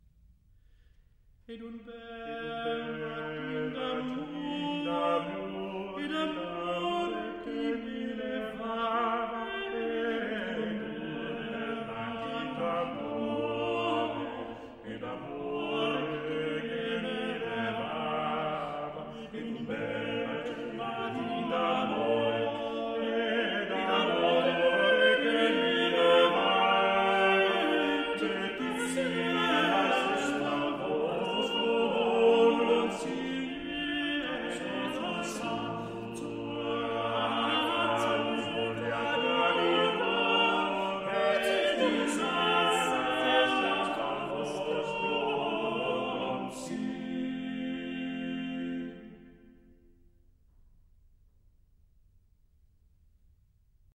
Madrigal
Group: A capella